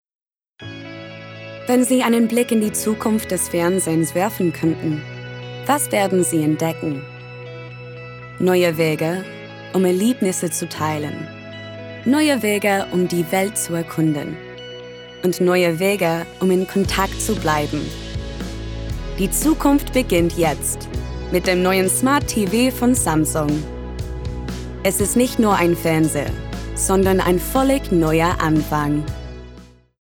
Young, Fresh with Warm Husky Tones
Promo, Cool, Smooth, Confident